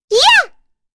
Rehartna-Vox_Jump_b.wav